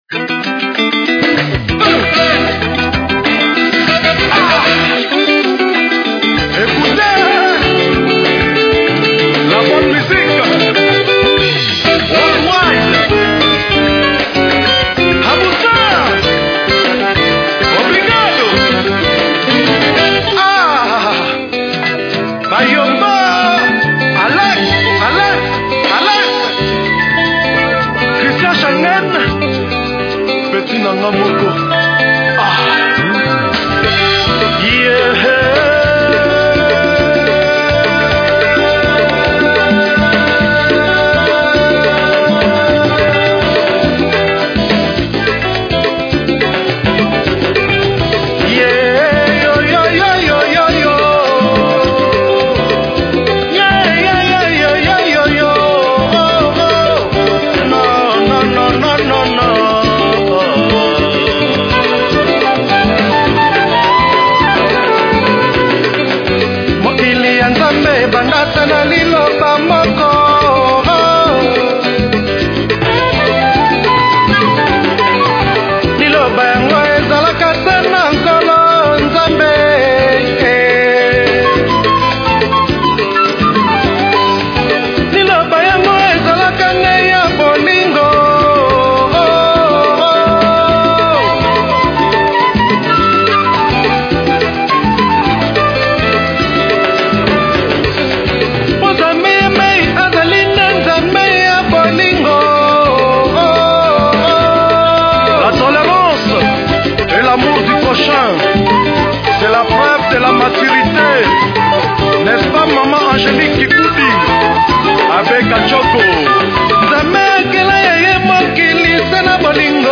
la rumba est à l’honneur
chanson patriotique